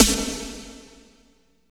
50.03 SNR.wav